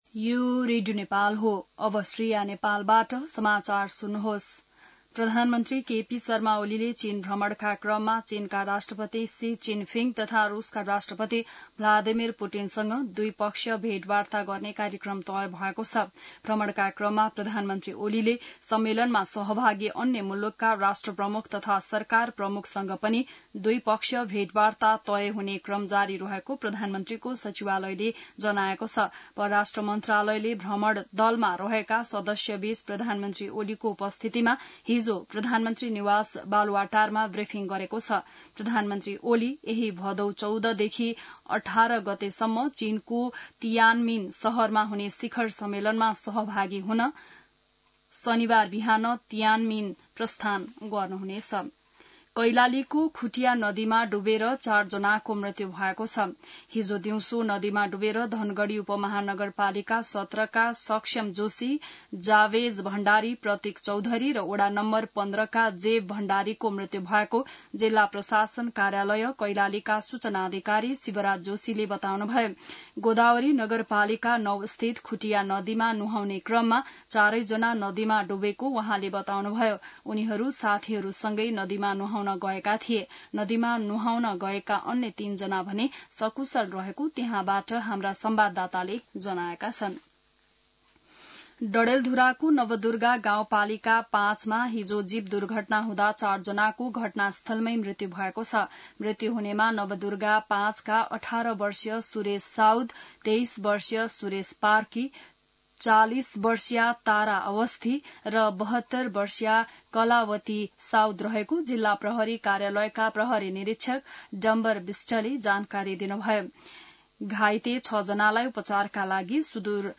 बिहान ६ बजेको नेपाली समाचार : १३ भदौ , २०८२